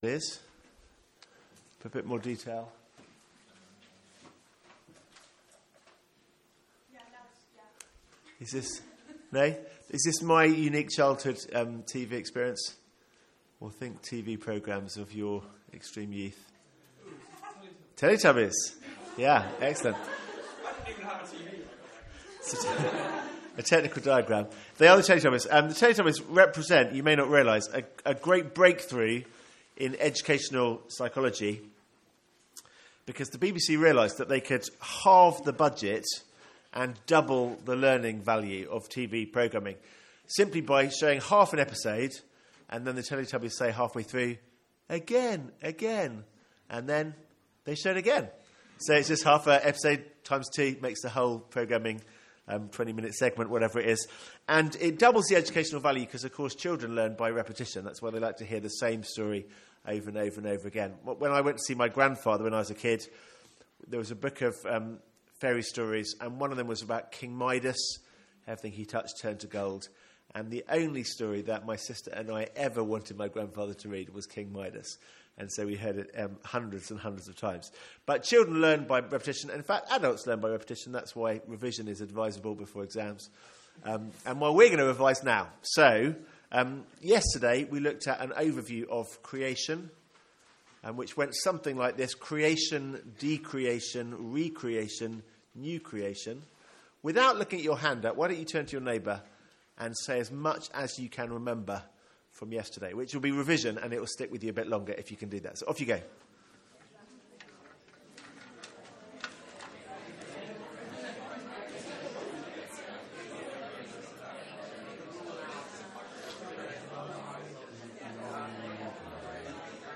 Sermons | St Andrews Free Church
From our student Mid-Year Conference.